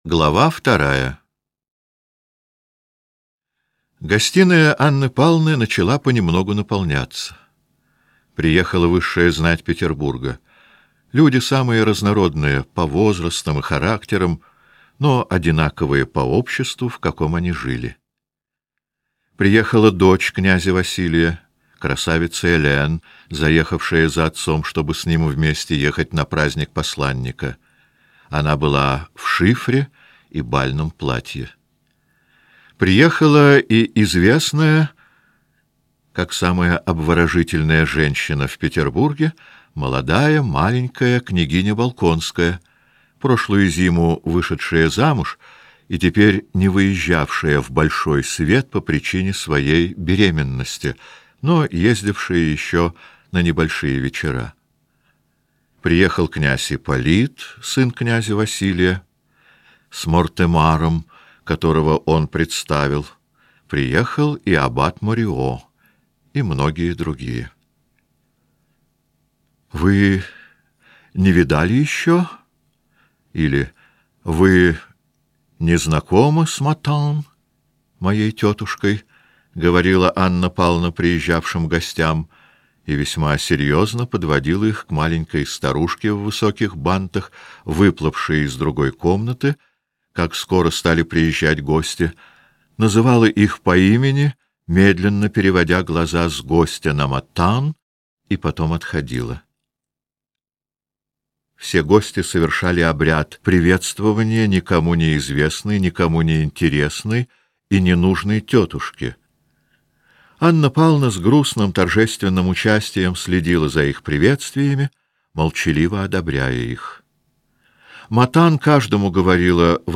Аудиокнига Война и мир. В 4-х томах | Библиотека аудиокниг